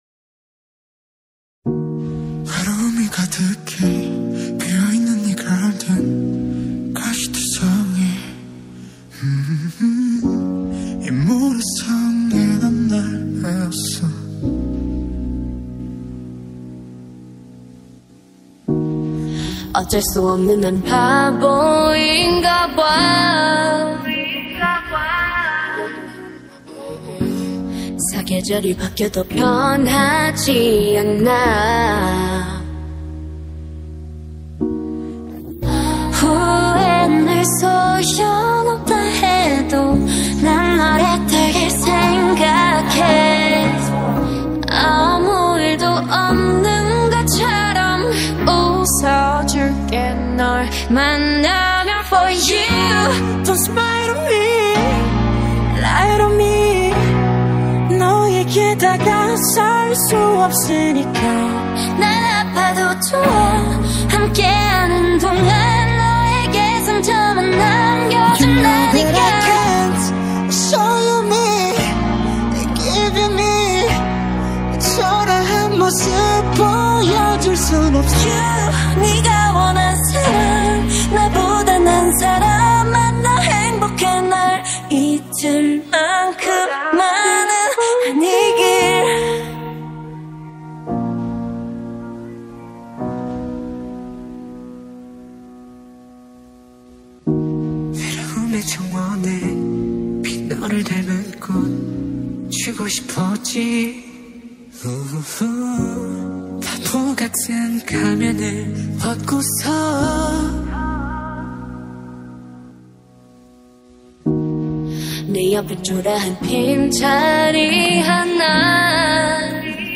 میتونم برای این مشاپ ساعت ها گریه کنم.